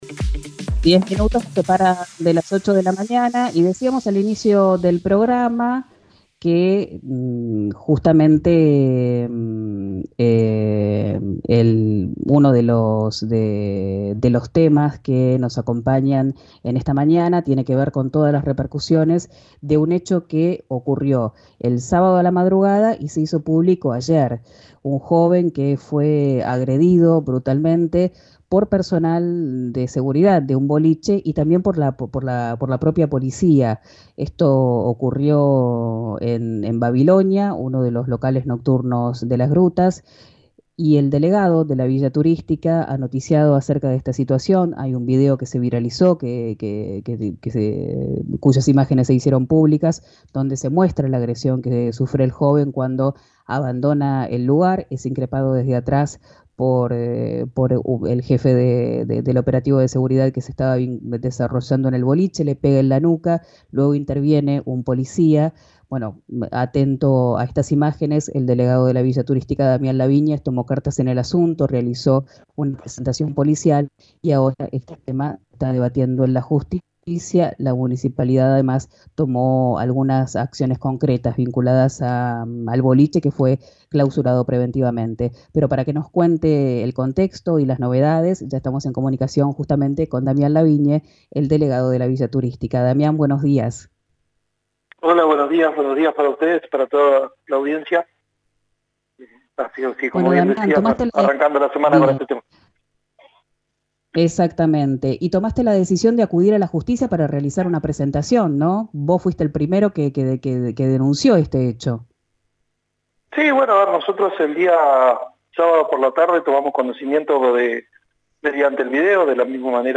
Damián Lavigne, uno de los funcionarios de Las Grutas que acusó a los agresores, explicó en RÍO NEGRO RADIO qué datos se conocen hasta el momento y qué pasó con la clausura del boliche.